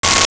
Sci Fi Beep 02
Sci Fi Beep 02.wav